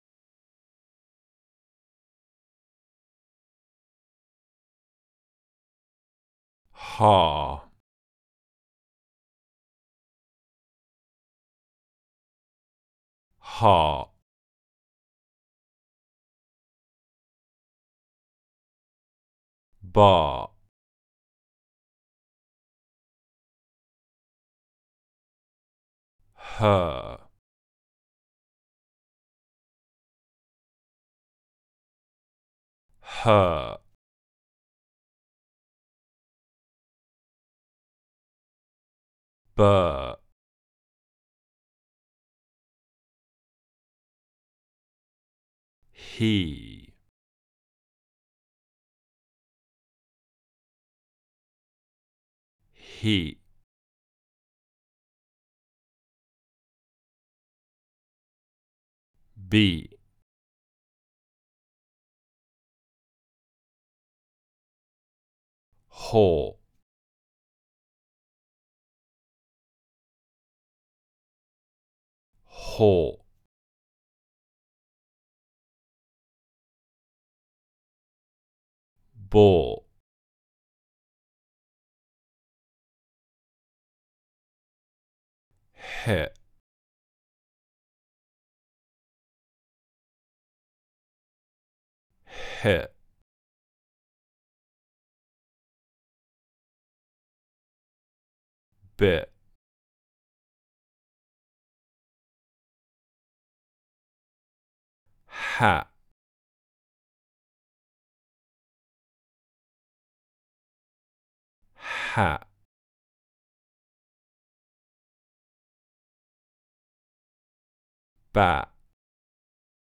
The Basic Building Blocks of Speech - Aspiration - British English Pronunciation RP Online Courses
Aspirated /b/ + stop